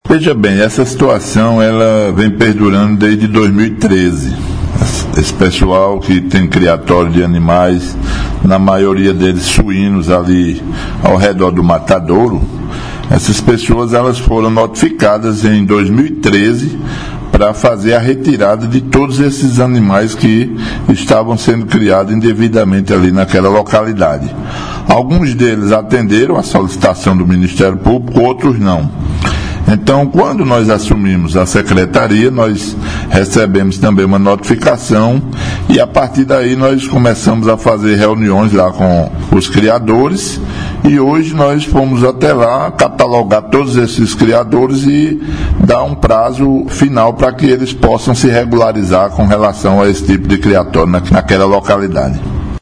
Fala do secretário de Meio Ambiente, Natércio Alves – Download